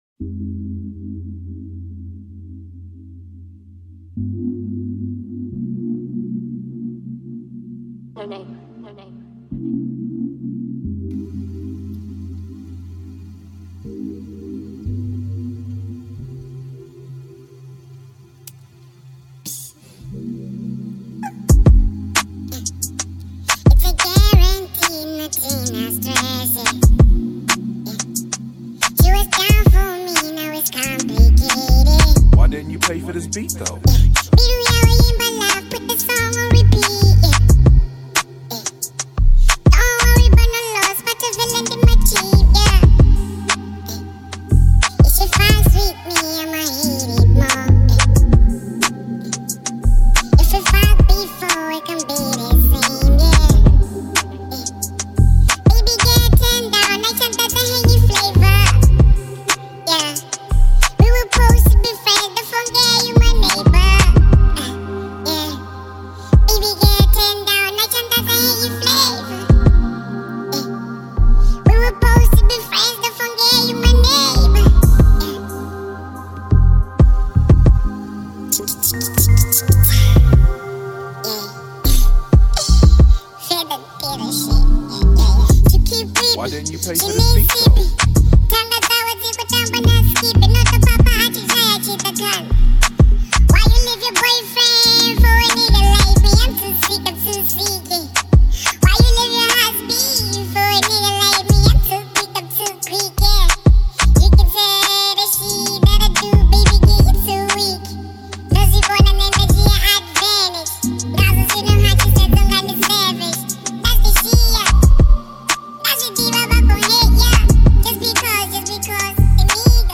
03:14 Genre : Venrap Size